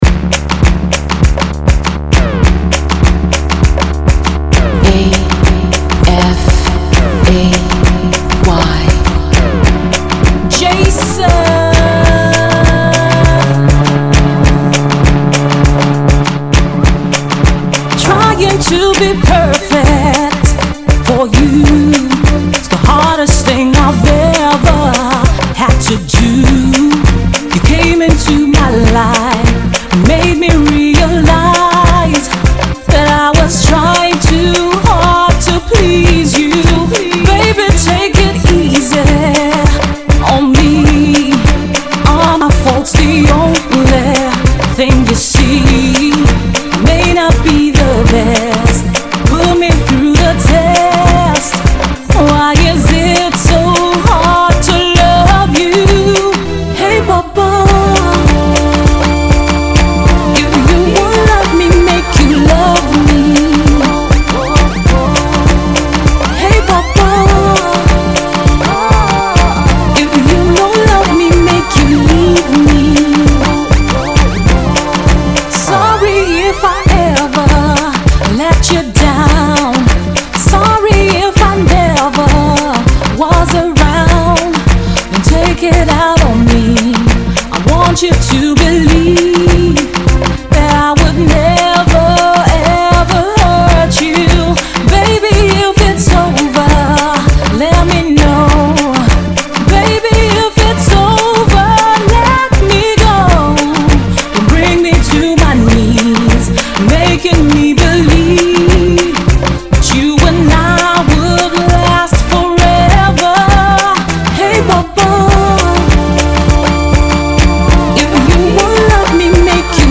Afro Jazz/Soul